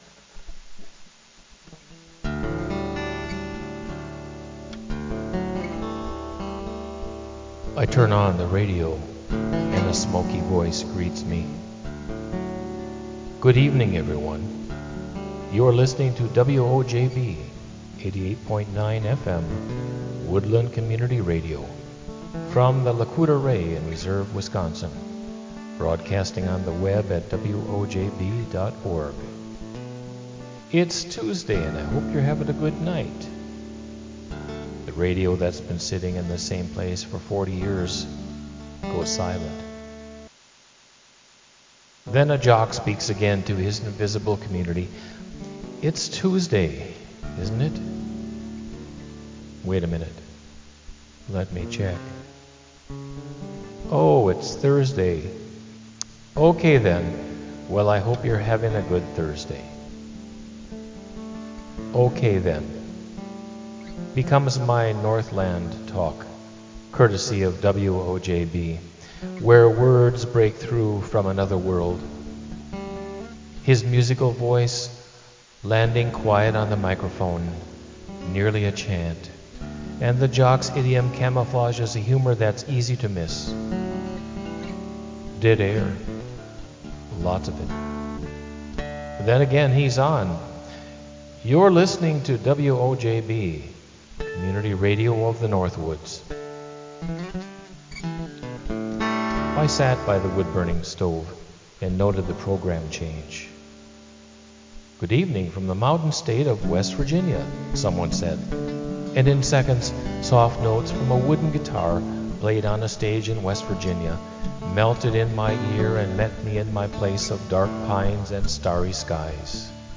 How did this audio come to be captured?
recorded in Mesa, AZ